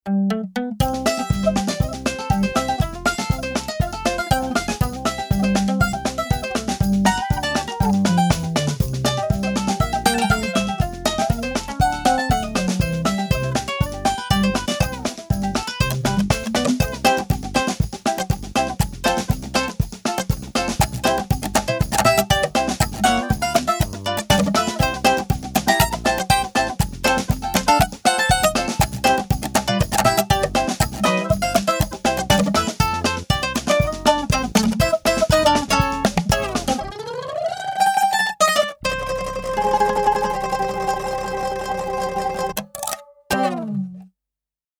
Mandolin_Demo01.mp3